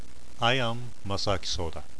[self-introduction]